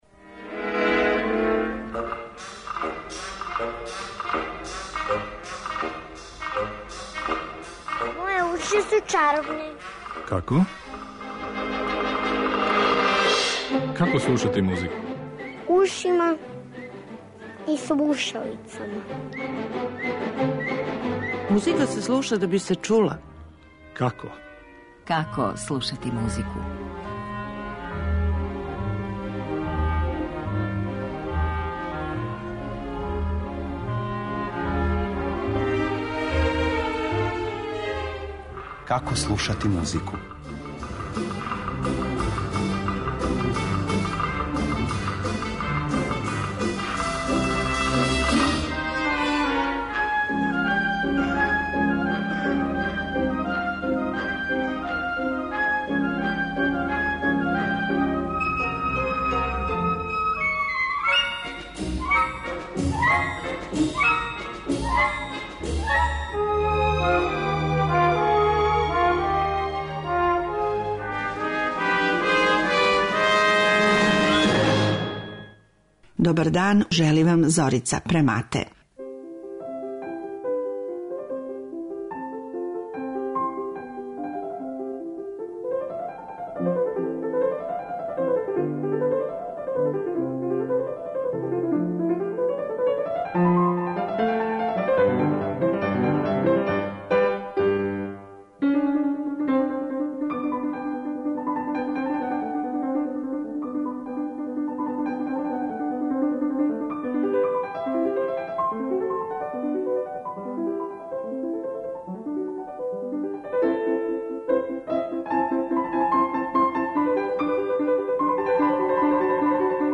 Уз одабране примере из опуса Хајдна, Бетовена и Моцарта